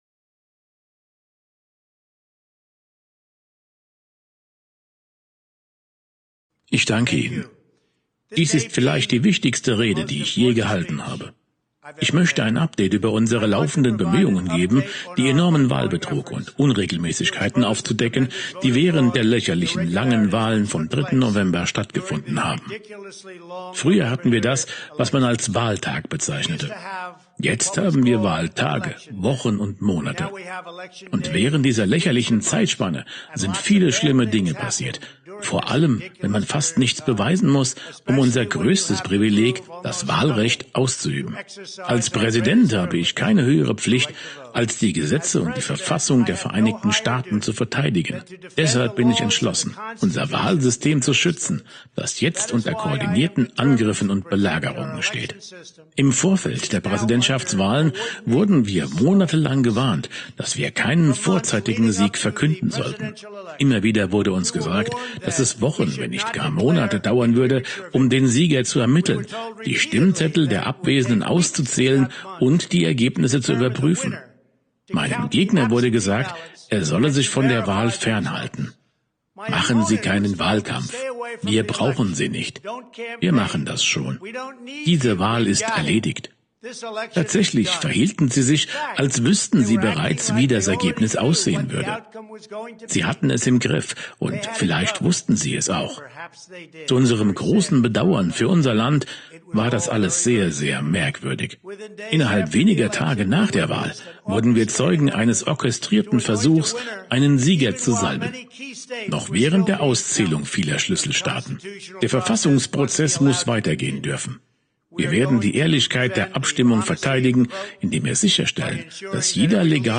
Die wahrscheinlich wichtigste Trump-Rede
Die Tonspur mit deutscher Übersetzung: